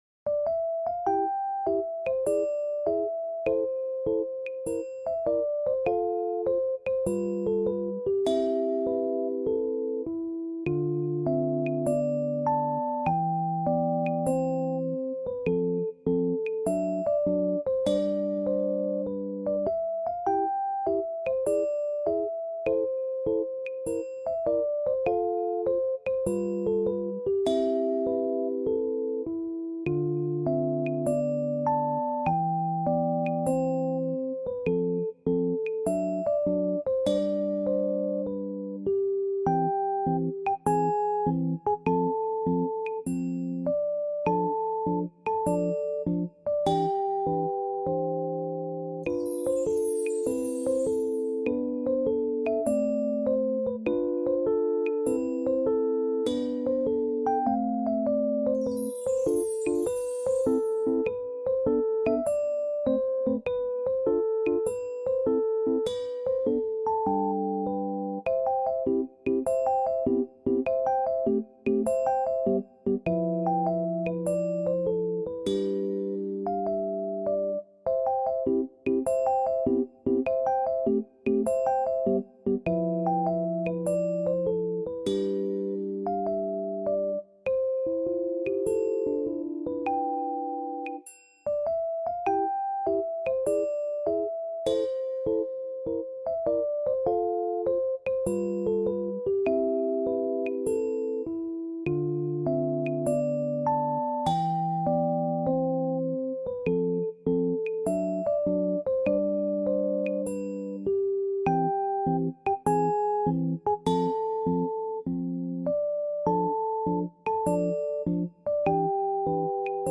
stimboard